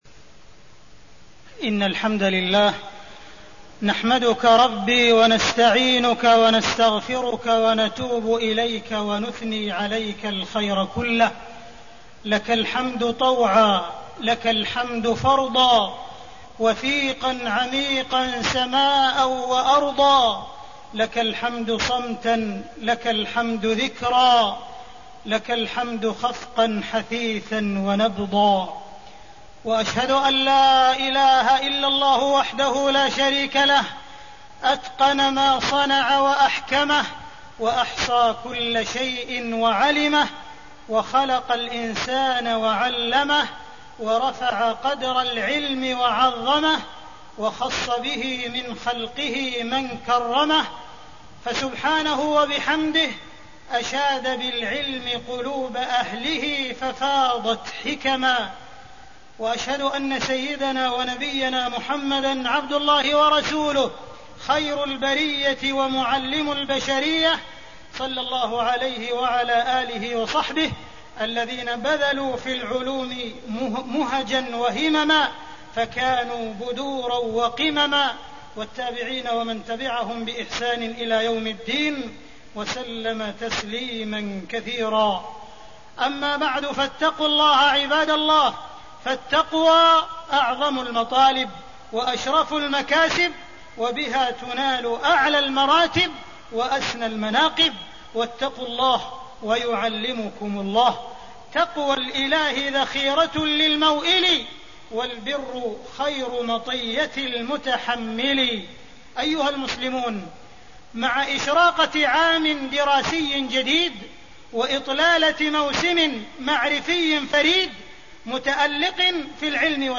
تاريخ النشر ٢٠ شوال ١٤٣٠ هـ المكان: المسجد الحرام الشيخ: معالي الشيخ أ.د. عبدالرحمن بن عبدالعزيز السديس معالي الشيخ أ.د. عبدالرحمن بن عبدالعزيز السديس قيمة العلم ورسالة للمعلمين